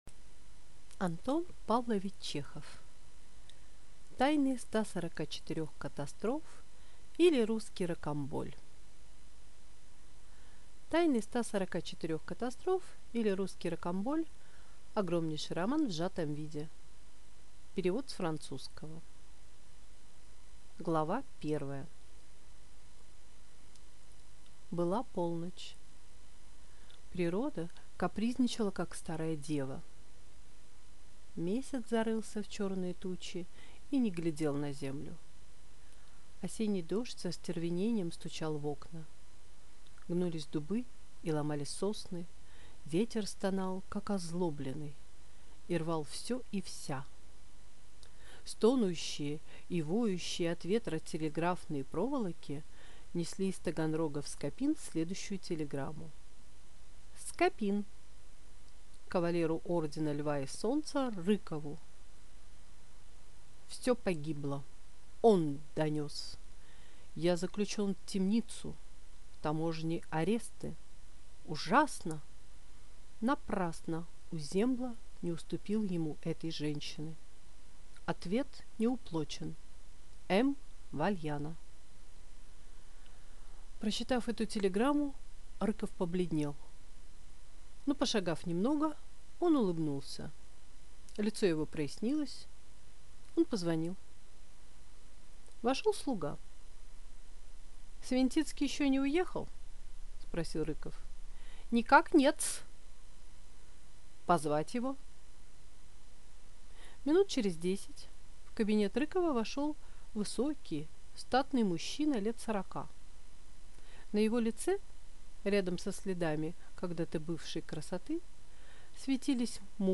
Аудиокнига Тайны 144 катастроф, или Русский Рокамболь | Библиотека аудиокниг